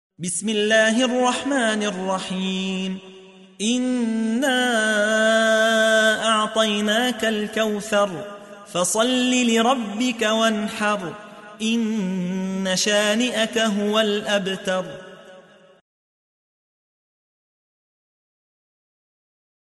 تحميل : 108. سورة الكوثر / القارئ يحيى حوا / القرآن الكريم / موقع يا حسين